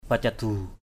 /pa-ca-d̪u:/ (đg.) làm cho lơi, nới ra, buông chùng= détendre, relâcher. pacadu talei mai F%cd~% tl] =m buông chùng dây lại.